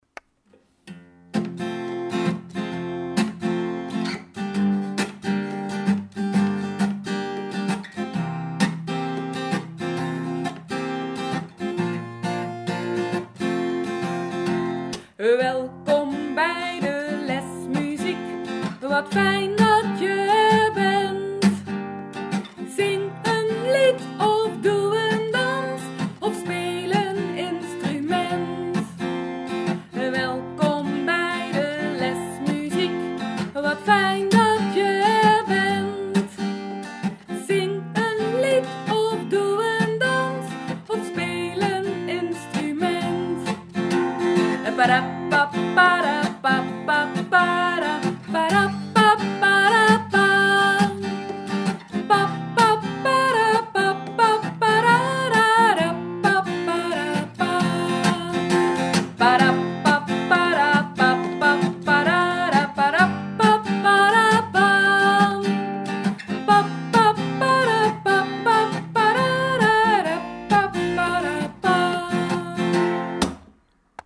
openings- en slotliedjes van de muziekles